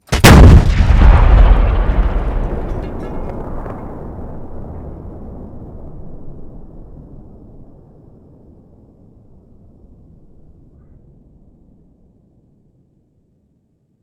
artillery-shoots-1.ogg